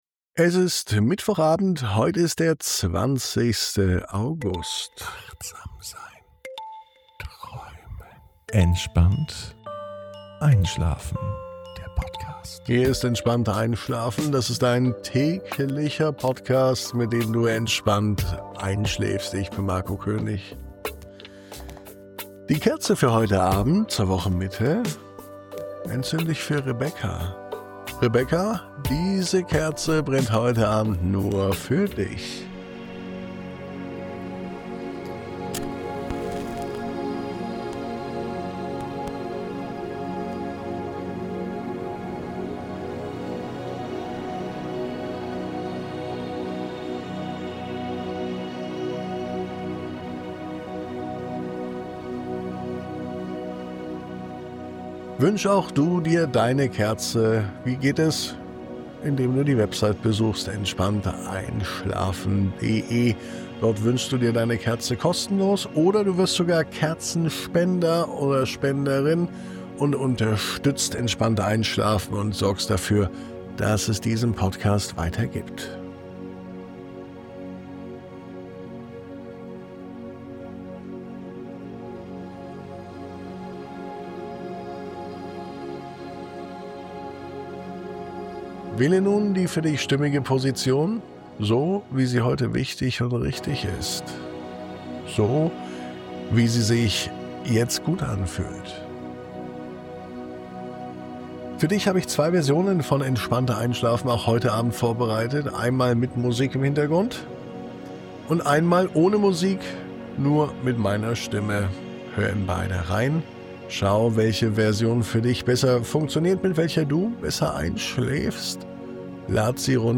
In dieser Traumreise entsteht ein Moment völliger Ruhe zwischen den Schwingungen.
0820_MUSIK.mp3